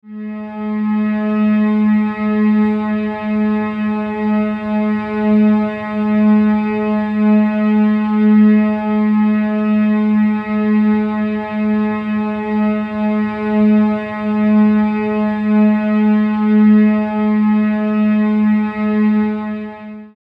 Music tones for String Animation Below
14_Medium_Gs.mp3